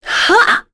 Lorraine-Vox_Attack2.wav